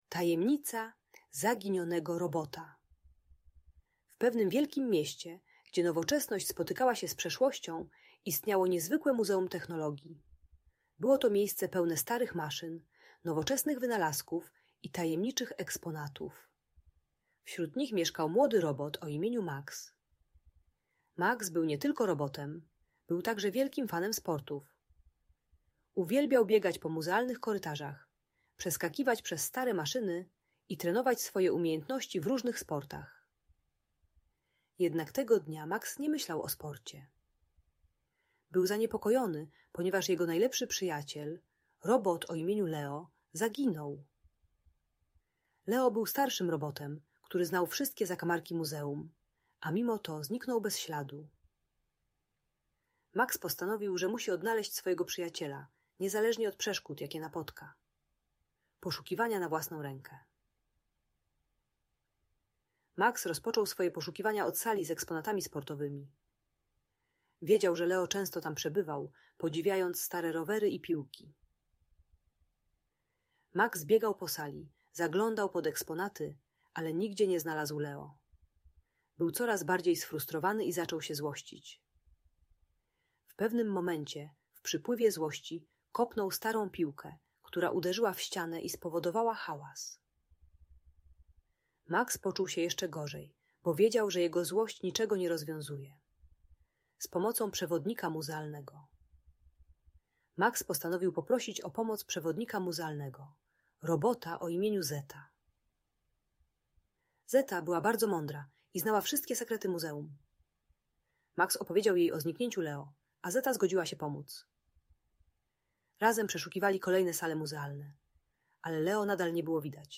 Tajemnica Zaginionego Robota - Fascynująca historia - Audiobajka